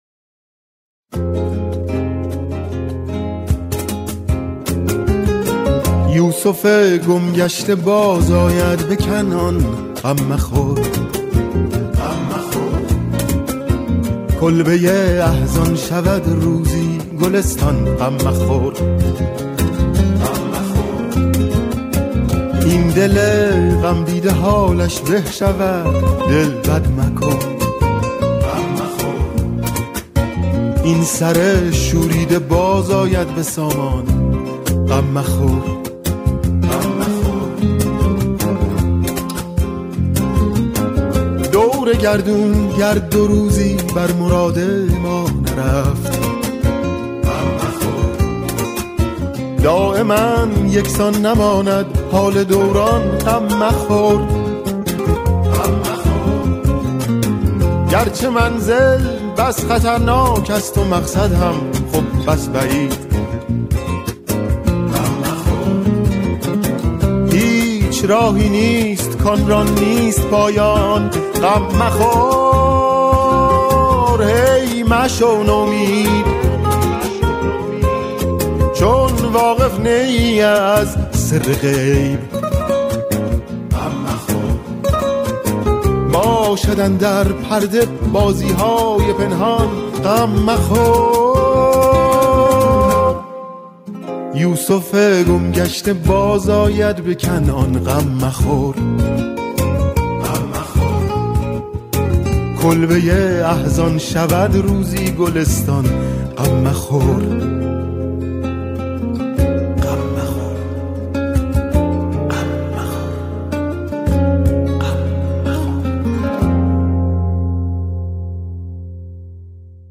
گروه کر